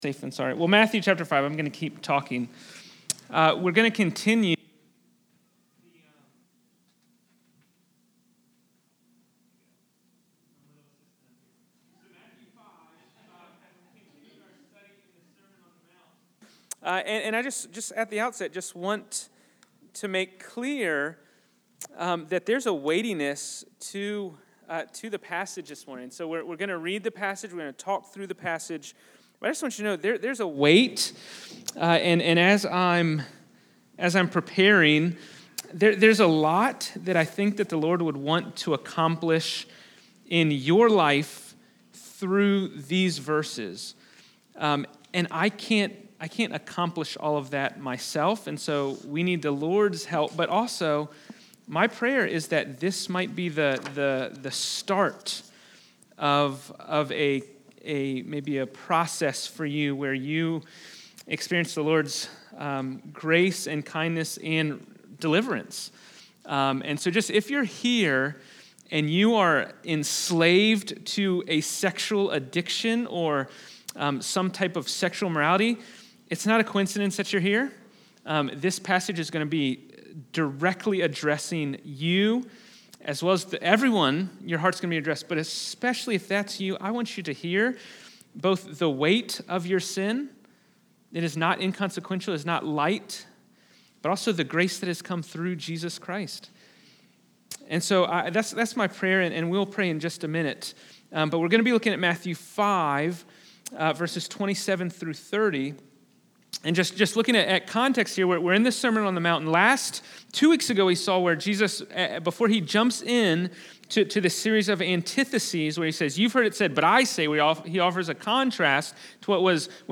Fox Hill Road Baptist Church Sermons